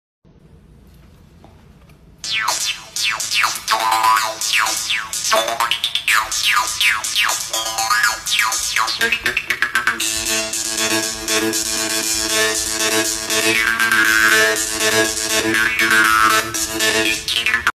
hacker music.mp3